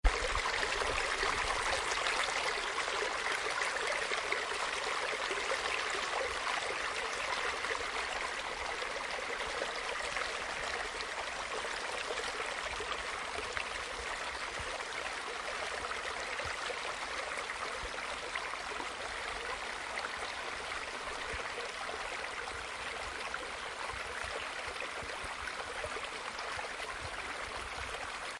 小溪
描述：水流在一条小溪中
标签： 汩汩 河流 溪流 液体 小溪 涓涓细流
声道立体声